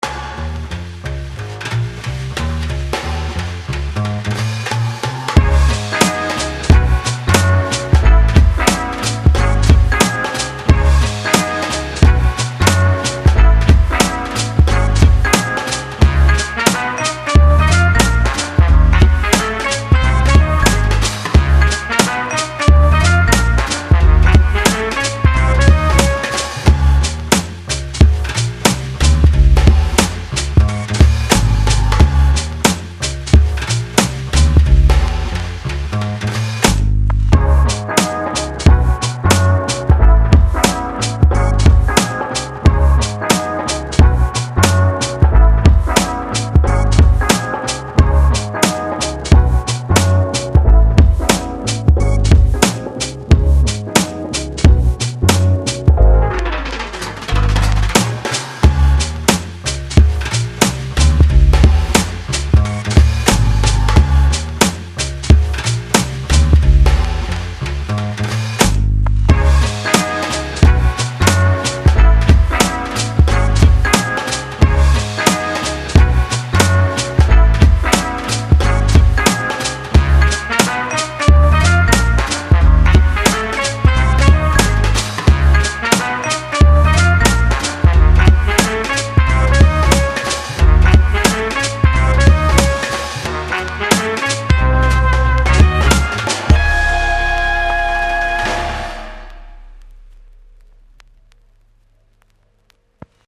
This beat is currently for sale.
Hip-hop
Rap
NuJazz